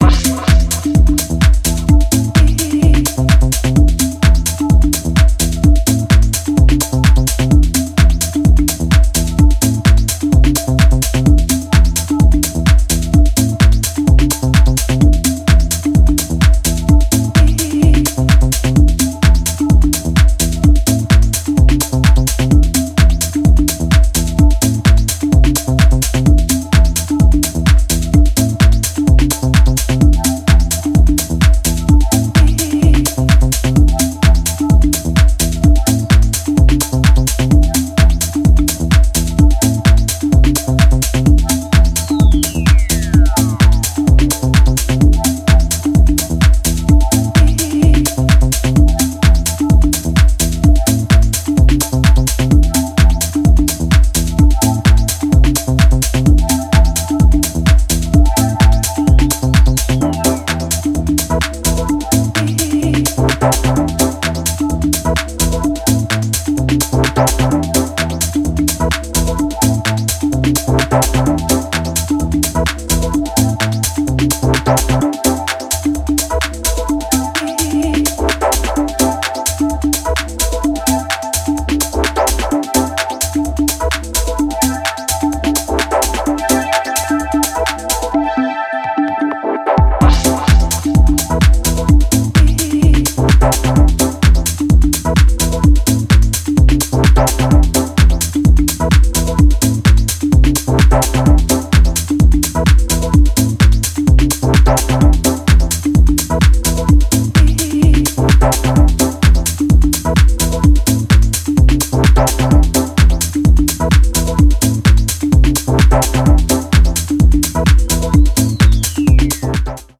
A useful, heartwarming EP of modern deep house music.